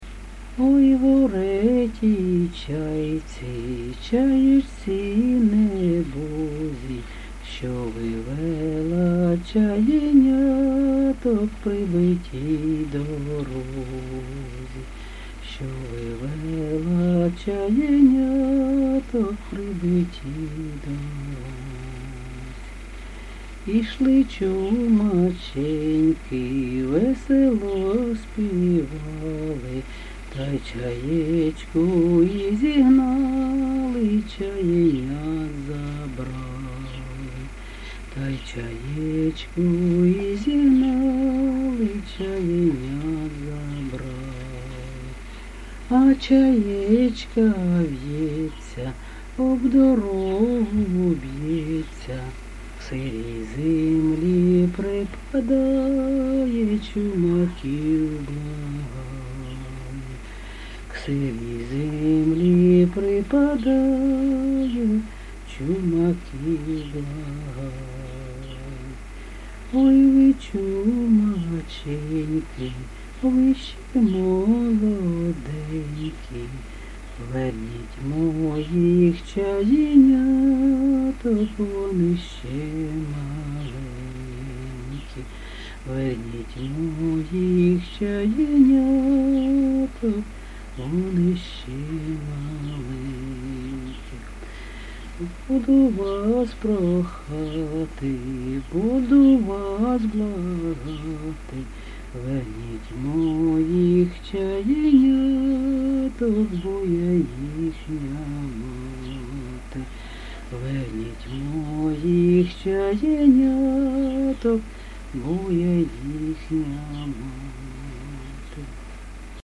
ЖанрПісні з особистого та родинного життя, Чумацькі
Місце записус. Серебрянка, Артемівський (Бахмутський) район, Донецька обл., Україна, Слобожанщина